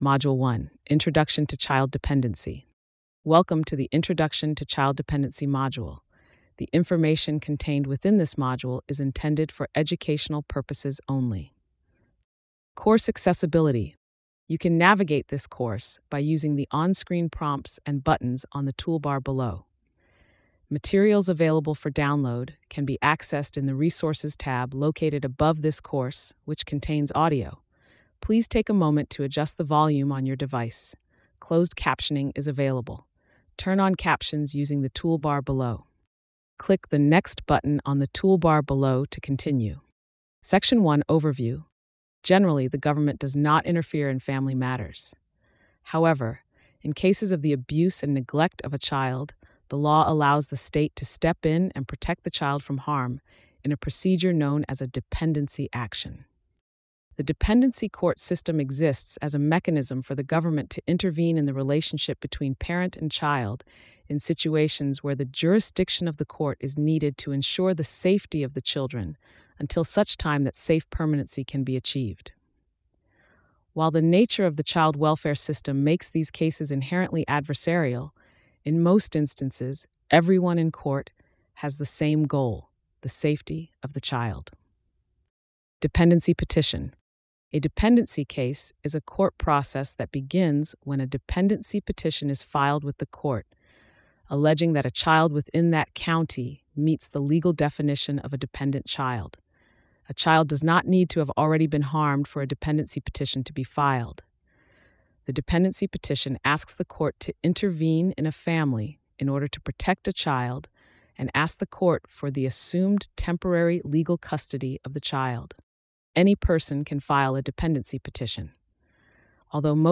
Module-1-Audio-Description.mp3